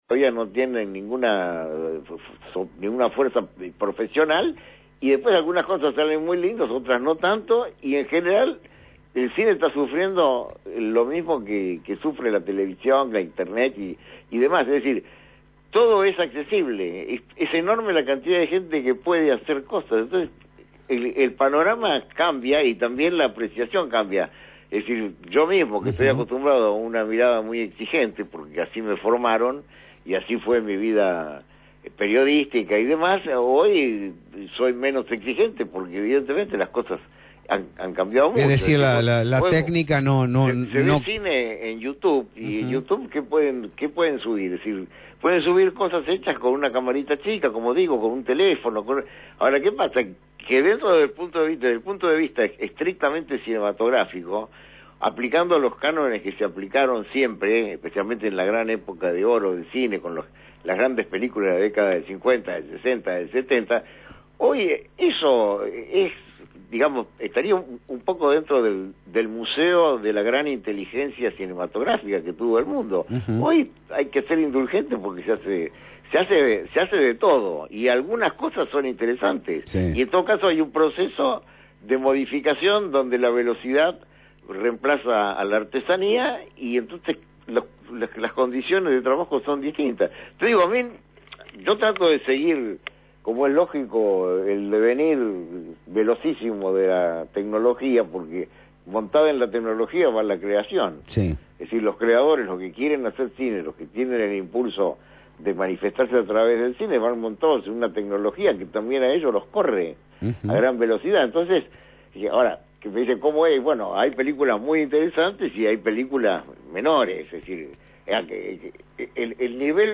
entrevistó a una autoridad sobre la materia: Rómulo Berruti.